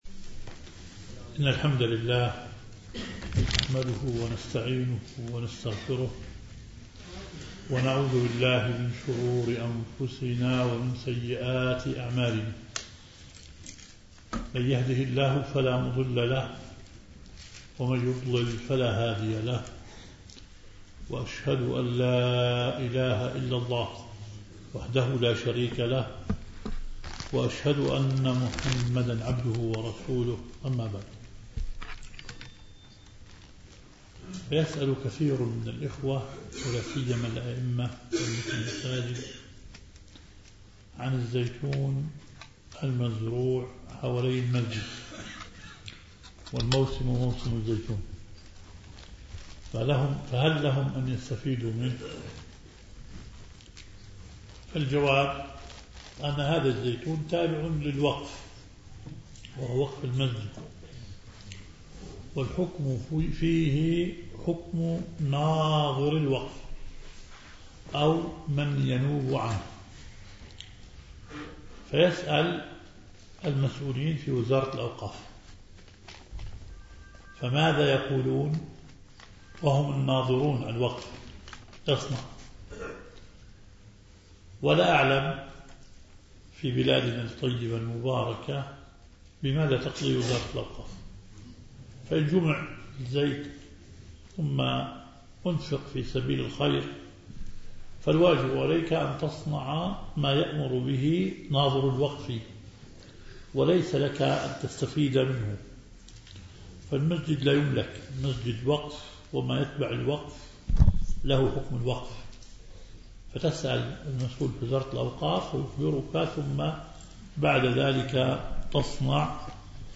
درس فجر الجمعة للسؤال والجواب ( جديد )